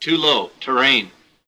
too-low-terrain.wav